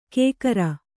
♪ kēkara